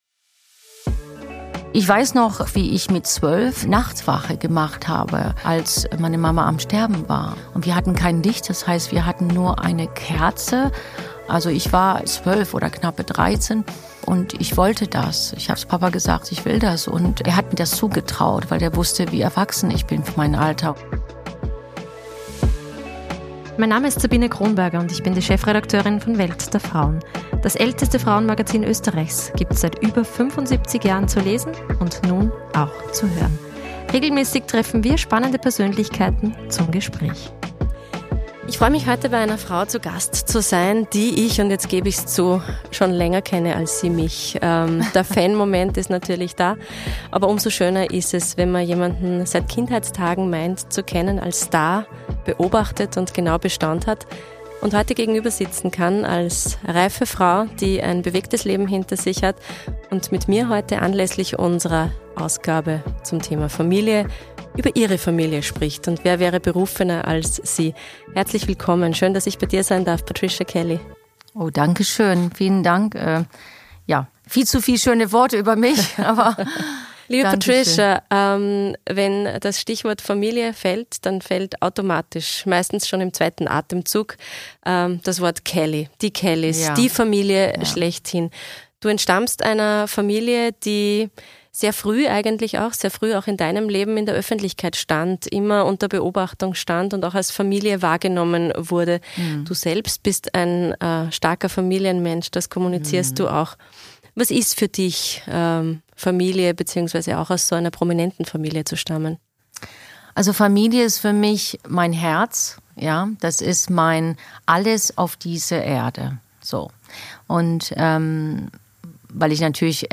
In einem berührenden Gespräch lässt Sängerin Patricia Kelly hinter diese Kulissen blicken. Sie erzählt von der Liebe ihrer Eltern, von der sie sich getragen fühlte, aber auch von dunklen Momenten ihres Lebens, darunter die Alkoholkrankheit ihres Vaters, der Verlust der Mutter, die Brustkrebserkrankung, die sie überwinden konnte, und das Glück, eine eigene Familie zu haben.